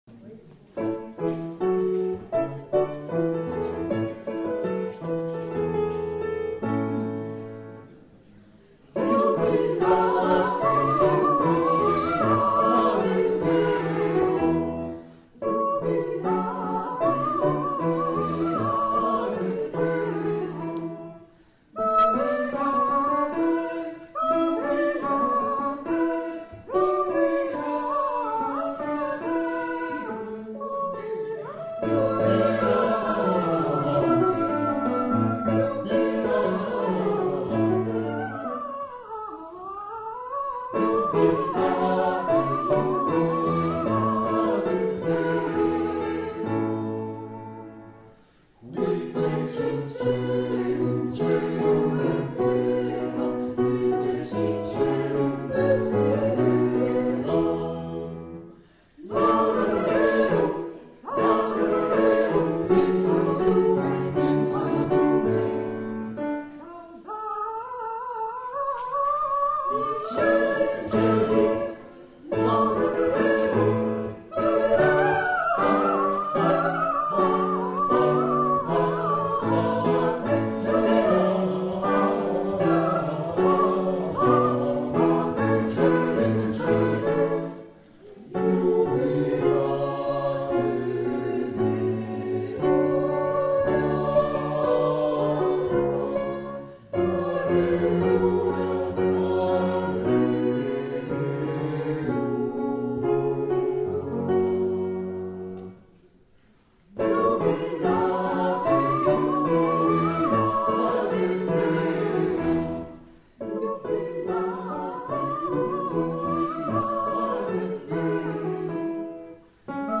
Ensemble vocal PlayBach Lambersart
audition à la Maison Jean XXIII